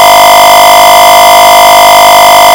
And finally, ring the bell.
32486 LD BC,32784 Set the parameters for the bell sound effect
bell.ogg